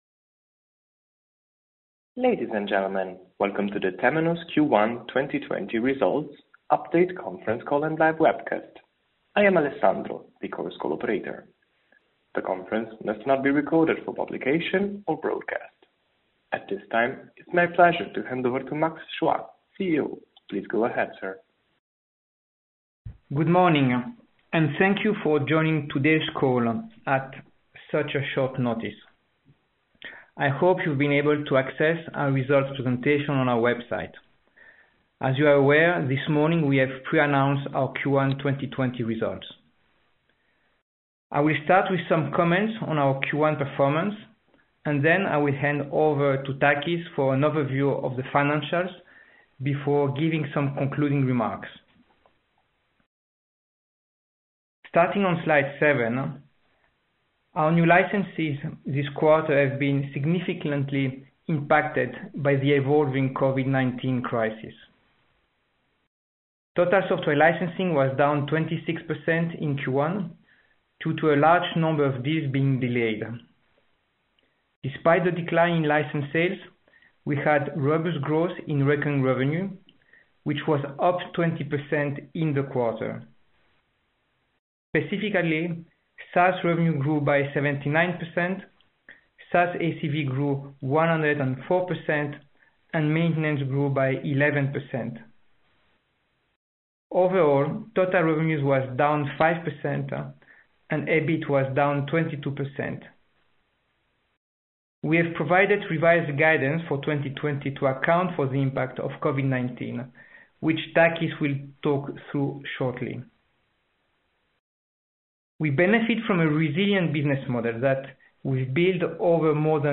Webcast-Recording-Q1-2020-Results.mp3